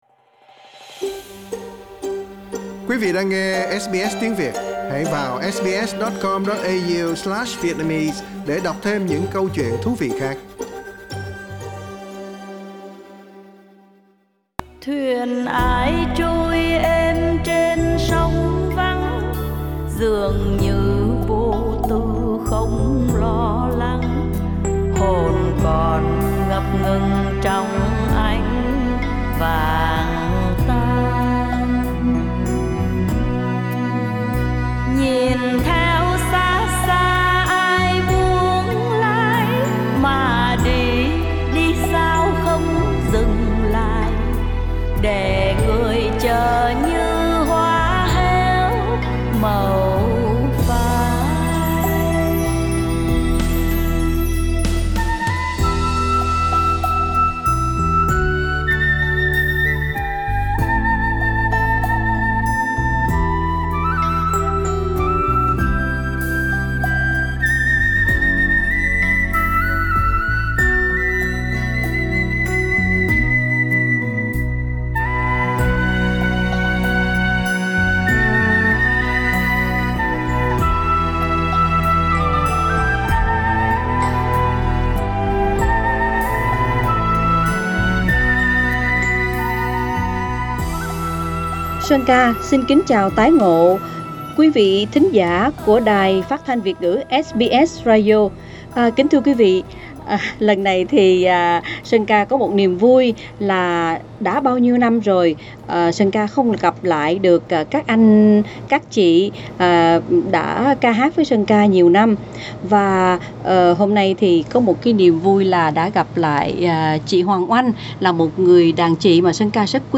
Ca sĩ Hoàng Oanh Source: Supplied